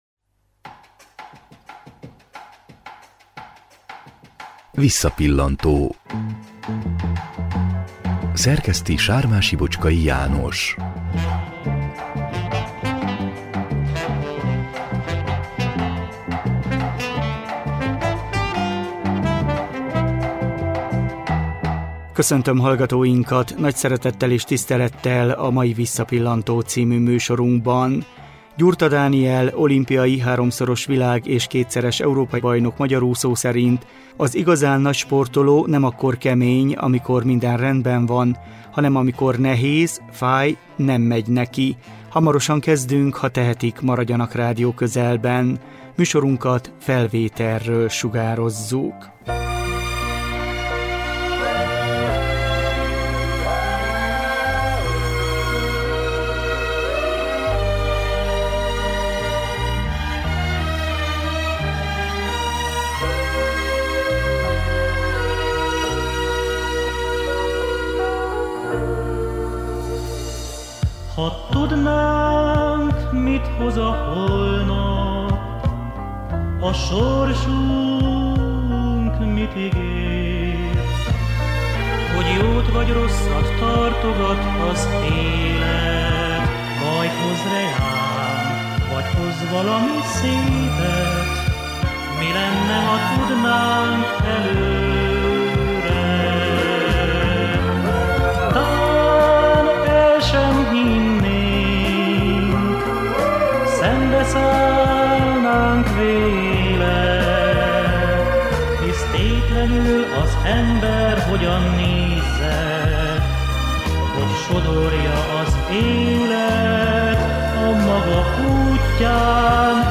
Az 1984-es évi nyári olimpiai játékokon Los Angelesben négy aranyérmet szerzett. Ma egy 2007-ben készült portrébeszélgetéssel elevenítjük fel pályafutását.